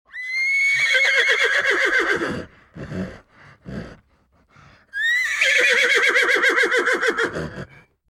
Horse Whinnies Téléchargement d'Effet Sonore
Horse Whinnies Bouton sonore